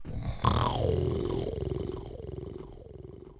snore.wav